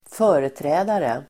Uttal: [²f'ö:reträ:dare]